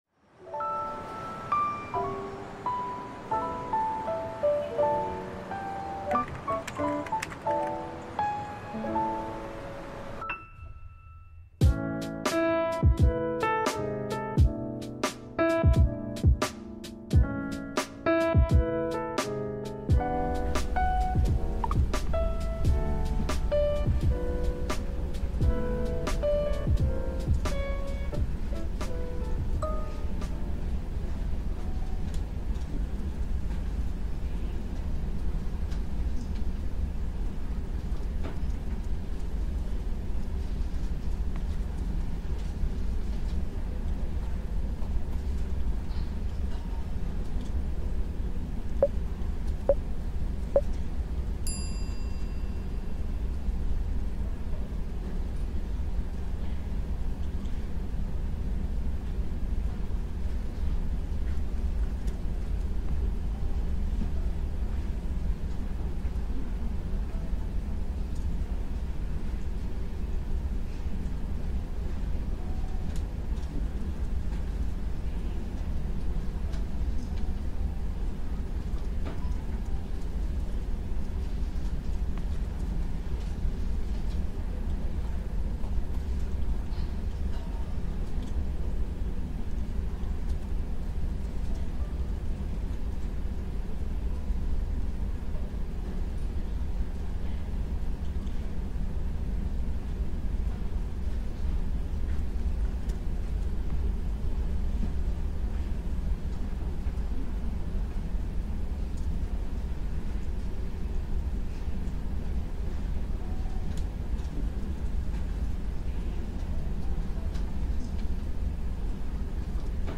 1-Hour Real-Time Café Ambience with ASMR and Background Noise